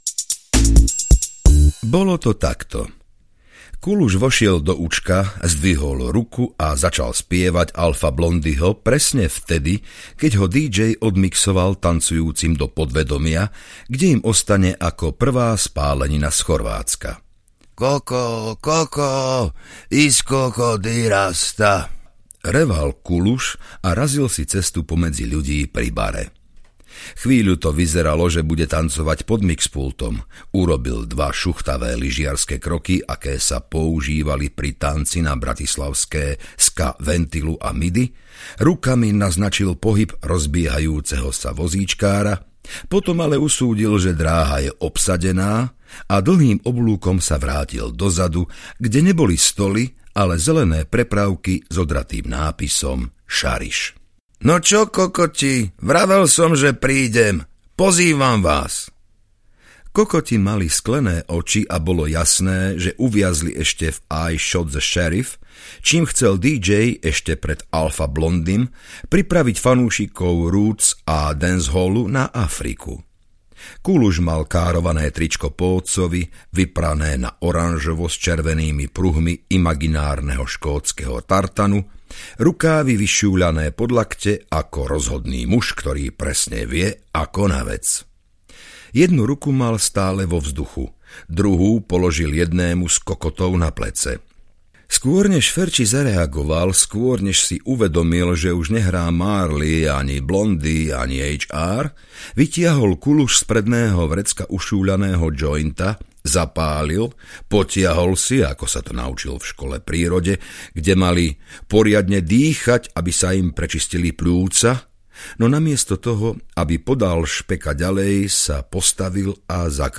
Analfabet audiokniha
Ukázka z knihy